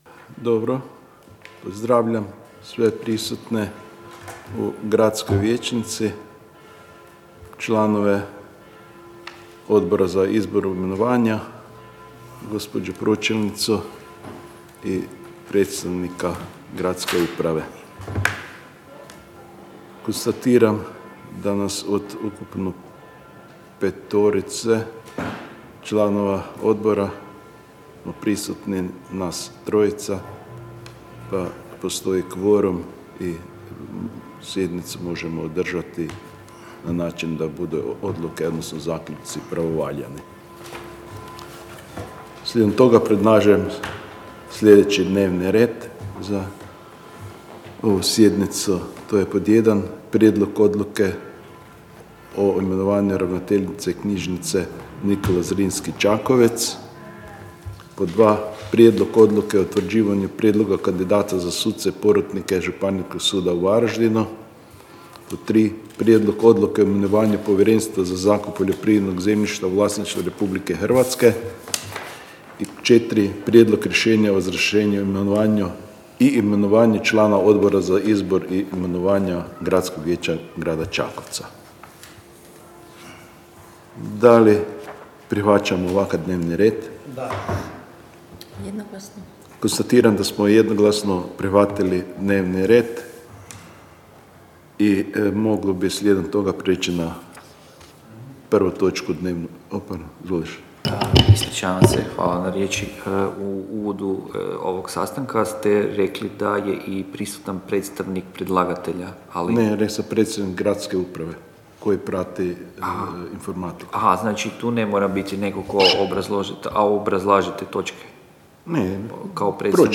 Obavještavam Vas da će se 5. sjednica Odbora za izbor i imenovanje Gradskog vijeća Grada Čakovca održati 25. ožujka 2022. (petak) u 12:00 sati, u vijećnici, u Upravi Grada Čakovca, K. Tomislava 15, Čakovec.